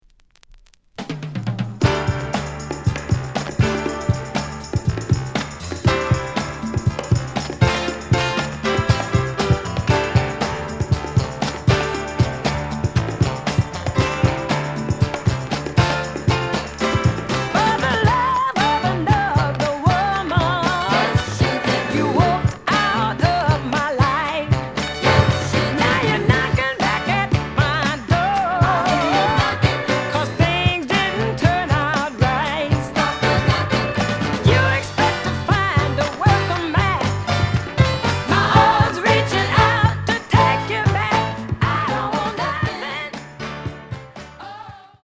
The listen sample is recorded from the actual item.
●Genre: Funk, 70's Funk